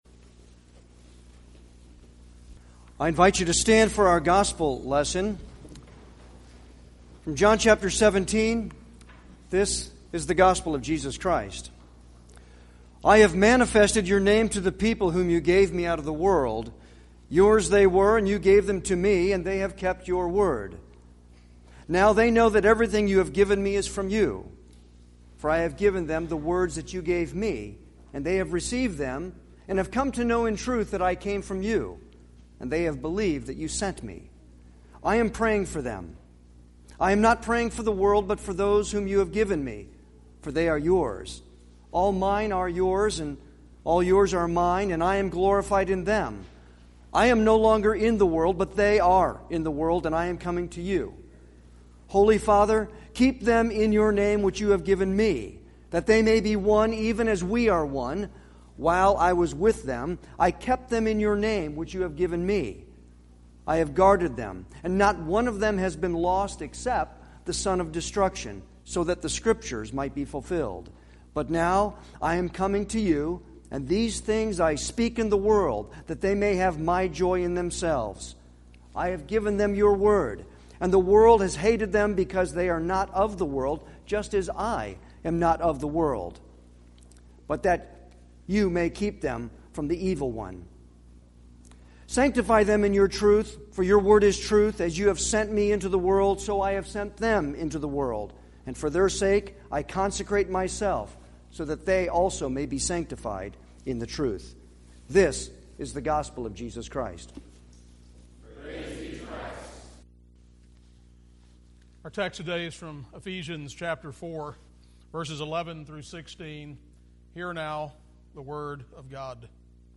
Series: Sermons by visiting preachers
Service Type: Sunday worship